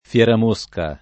[ f LH ram 1S ka ]